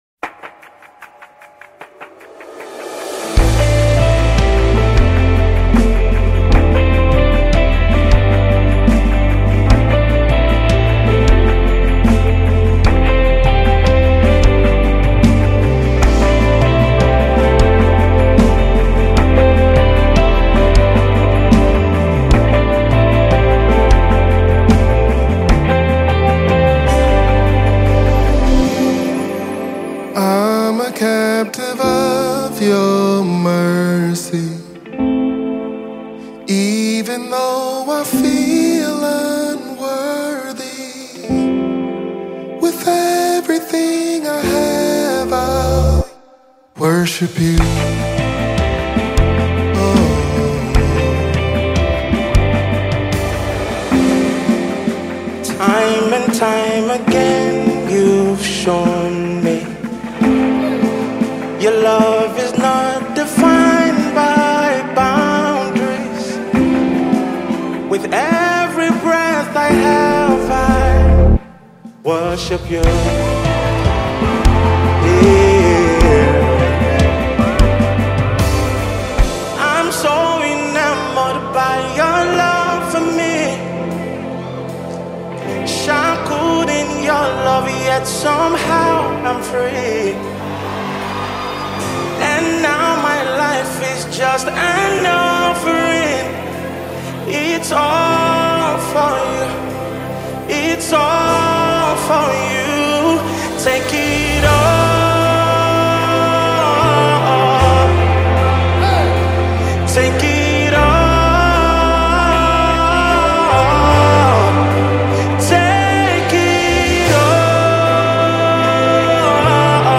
gospel music
featuring the soulful vocals